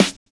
Snare set 2 004.wav